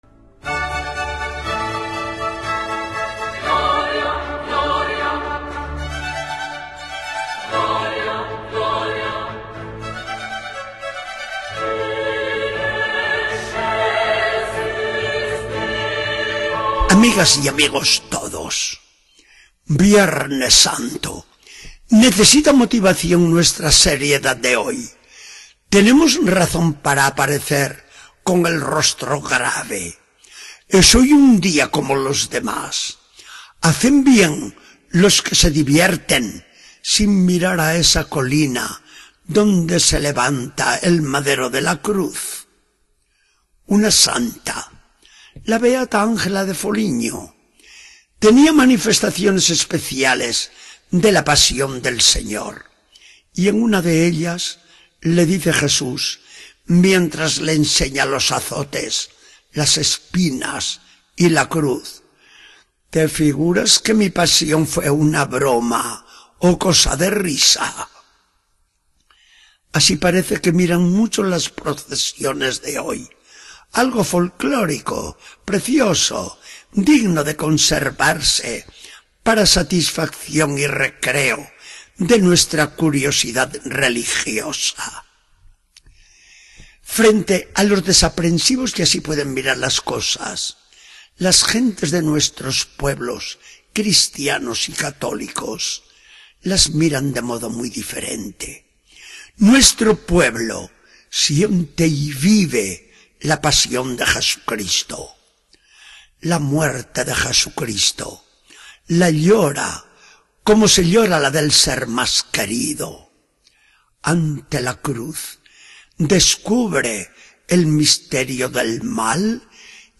Charla del día 18 de abril de 2014.